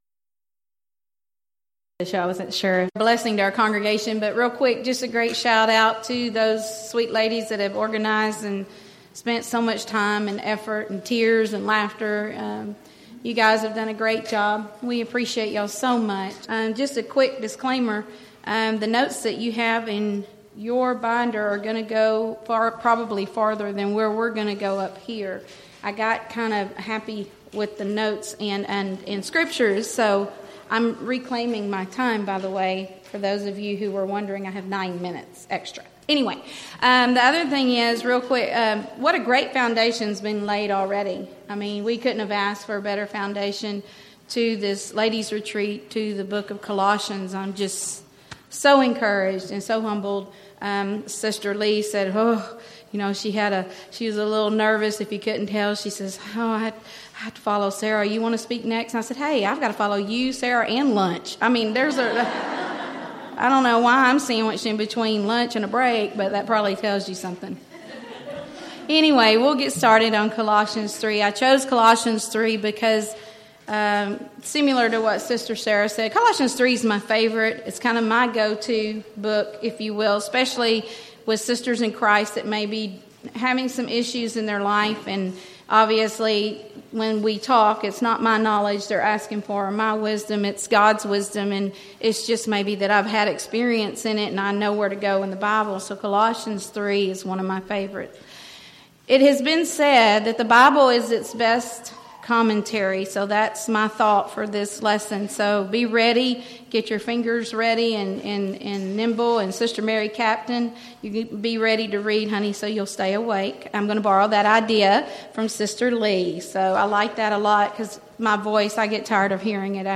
Event: 2014 Texas Ladies in Christ Retreat
Ladies Sessions